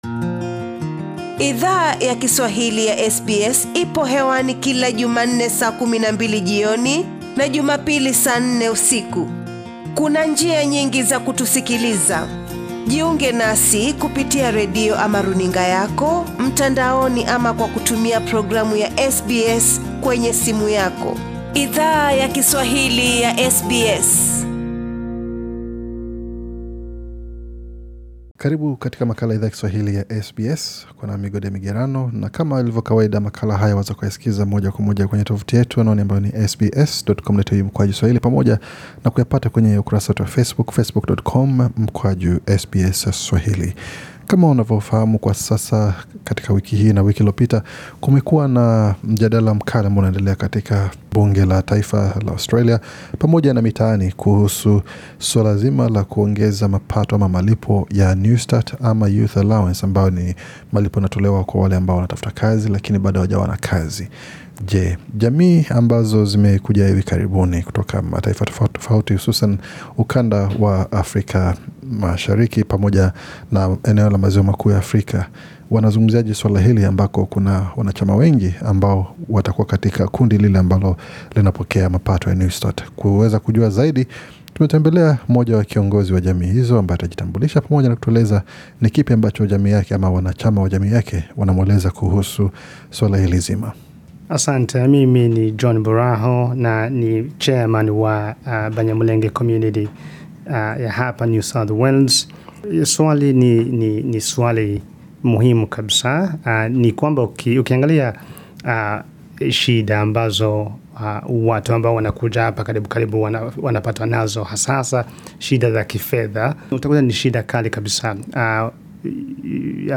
SBS Swahili ilizungumza na kiongozi wa jamii, aliyetueleza baadhi ya changamoto zinazo wakabili baadhi ya wanachama wake wanao pokea malipo ya Newstart.